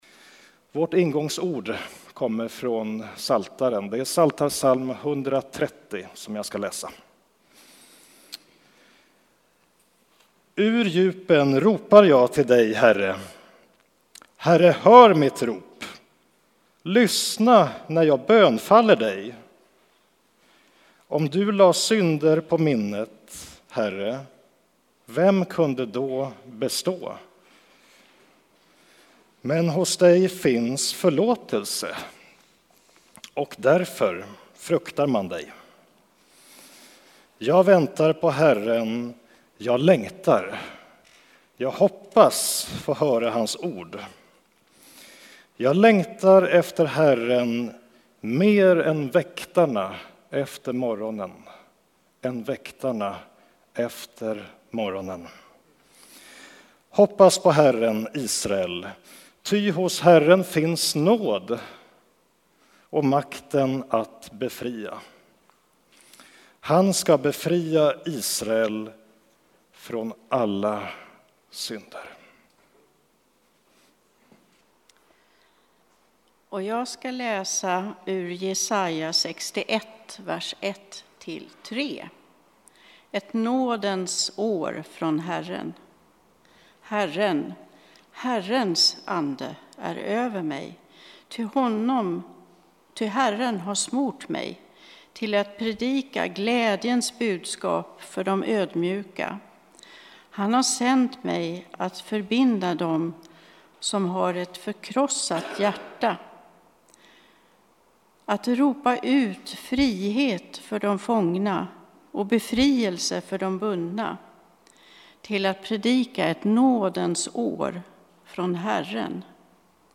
Textläsning, predikan och välsignelsenPs 130:1-8, Jesaja 61:1-3, Hebr 11:23-27, Markus 14:3-9